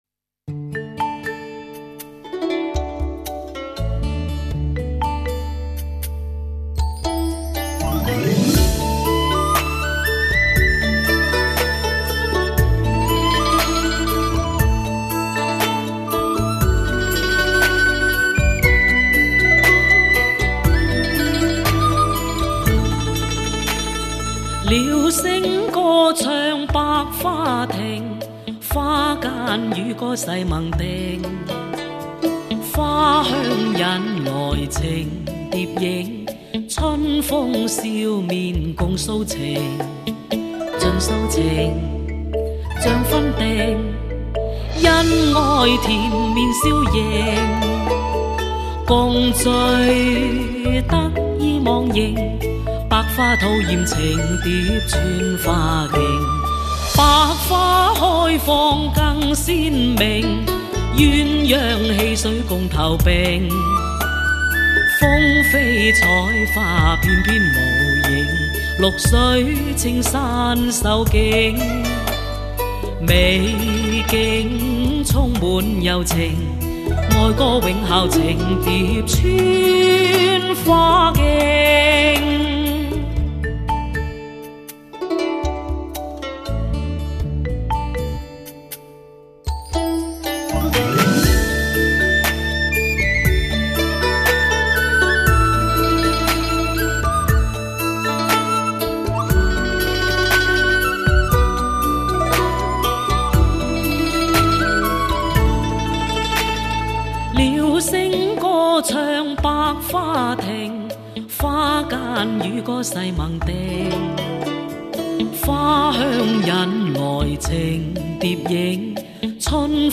集小曲、粤曲、唱腔及流行小调于一炉，耳熟能详。
融铿锵与柔情行腔之中，刚柔相济。